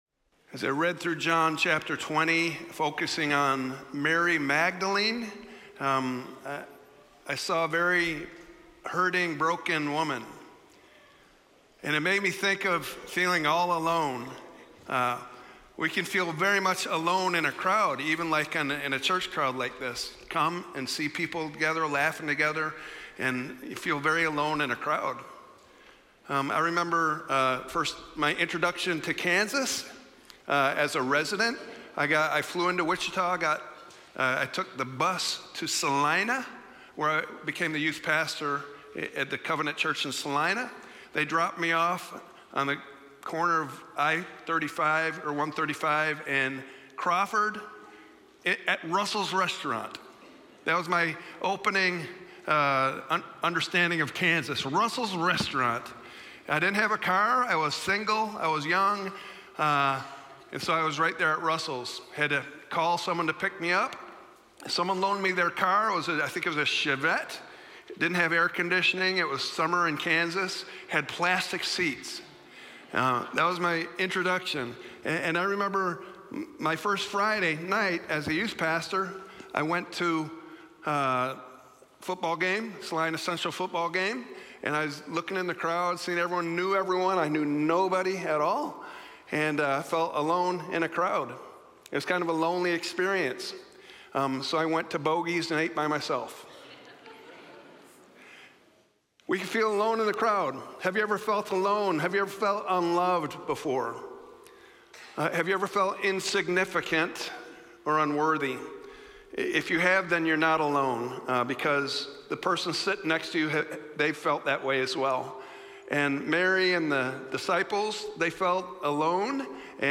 Sermons | Countryside Covenant Church